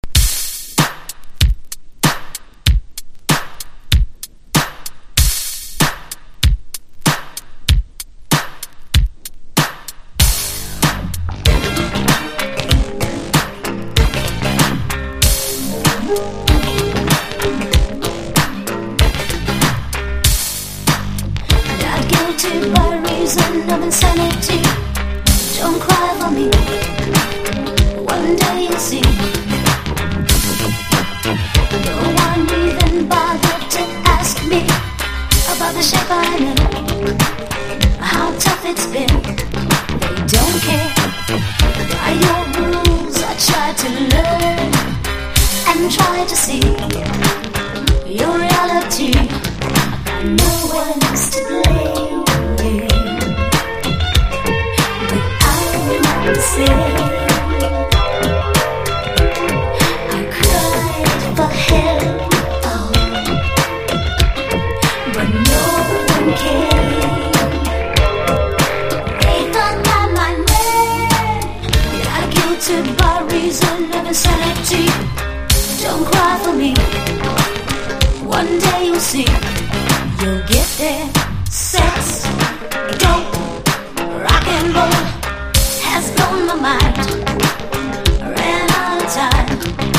ポップさも兼ね備えたミッド・ファンク・ナンバー！